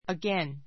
again 小 A1 əɡén ア ゲ ン 副詞 （ ⦣ 比較変化なし） ❶ もう一度 , また ; 否定文で 二度と Try again.